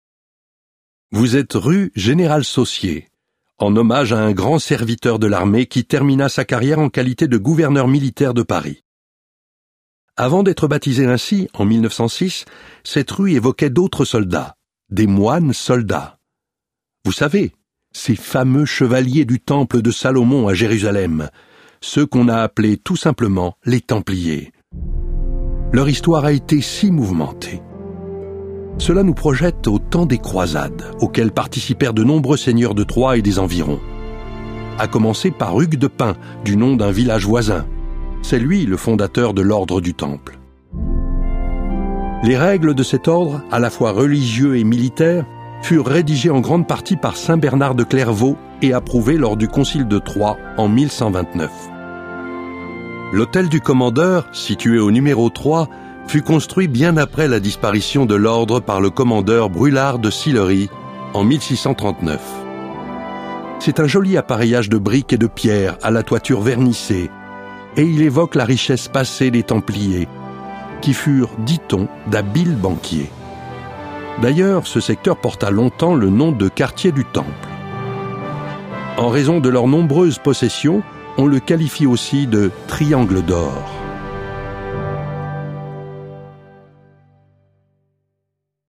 Cette balade audio-guidée vous permet de découvrir par vous-même tous les lieux importants de la ville de Troyes, tout en bénéficiant des explications de votre guide touristique numérique.